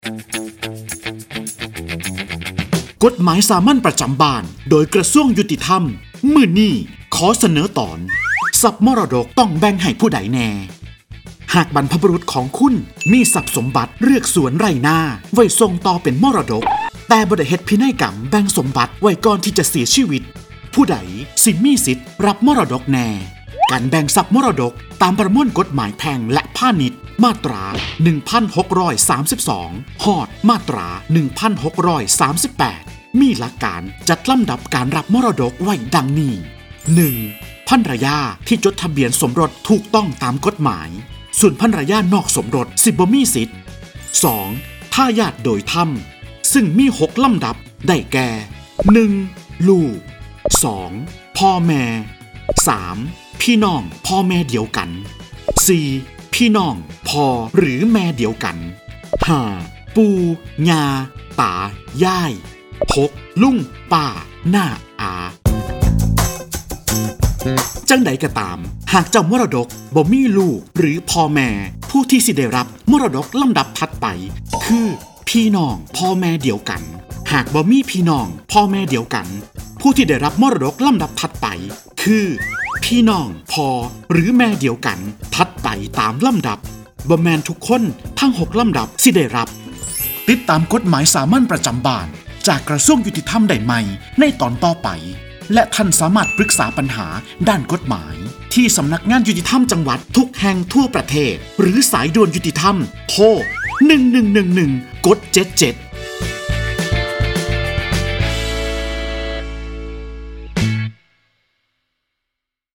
กฎหมายสามัญประจำบ้าน ฉบับภาษาท้องถิ่น ภาคอีสาน ตอนทรัพย์มรดกต้องแบ่งให้ใครบ้าง
ลักษณะของสื่อ :   คลิปเสียง, บรรยาย